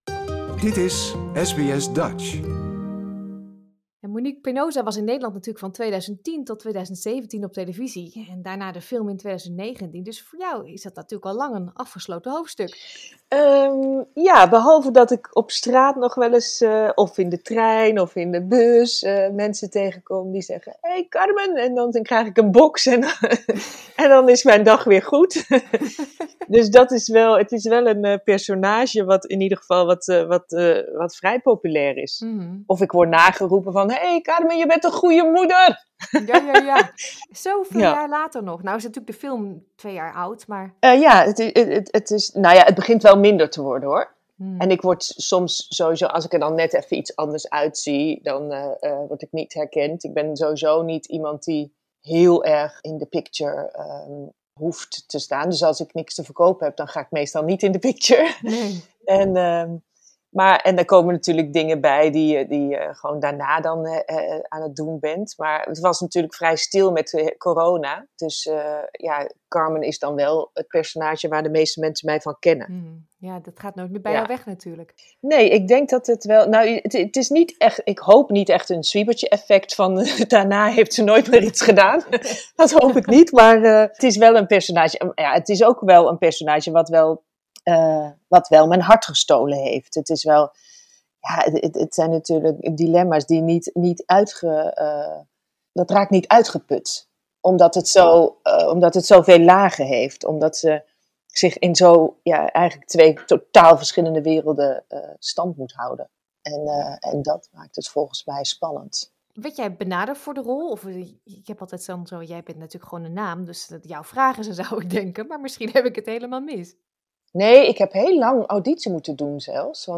Het gaat over Carmen van Walraven, moeder van 3 kinderen, die door omstandigheden aan het hoofd van een criminele drugsorganisatie komt te staan. De rol van Carmen wordt gespeeld door actrice Monic Hendrickx en wij spraken met haar (deel 1).